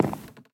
Minecraft Version Minecraft Version snapshot Latest Release | Latest Snapshot snapshot / assets / minecraft / sounds / block / dried_ghast / step / wood4.ogg Compare With Compare With Latest Release | Latest Snapshot
wood4.ogg